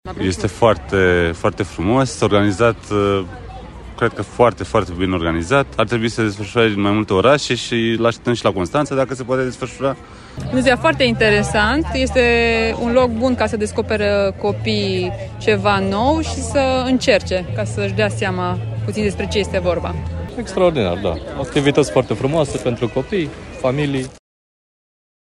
Şi adulții sunt fascinați de activitățile pregătite la Brașov:
vox-adulti-Street-Delivery-Brasov.mp3